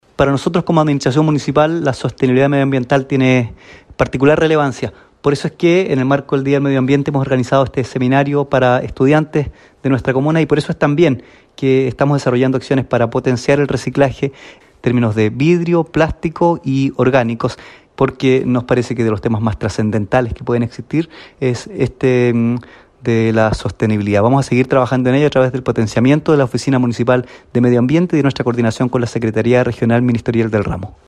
En la ceremonia de inauguración, el Alcalde de Frutillar, César Huenuqueo, destacó la importancia de este tipo de eventos y se refirió al rol que juega la protección del medio ambiente para su gestión comunal.
SEMINARIO-MEDIO-AMBIENTE-FRUTILLAR-ALCALDE.mp3